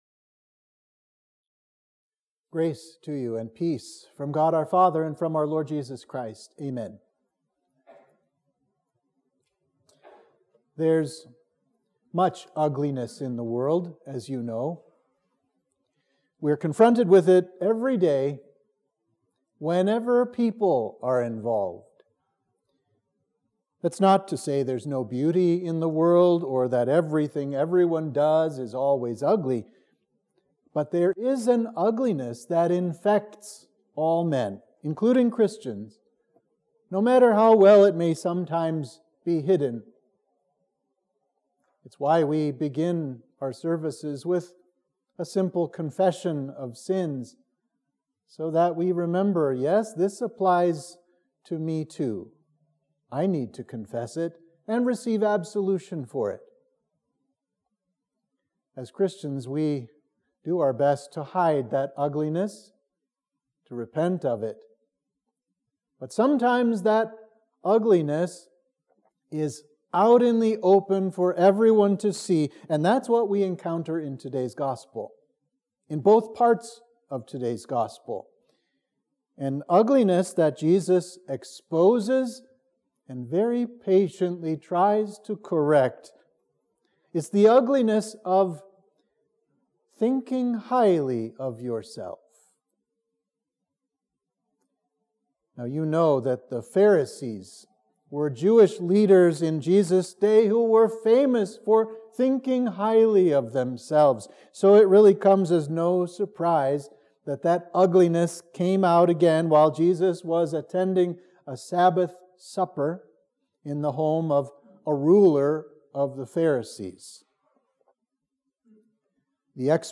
Sermon for Trinity 17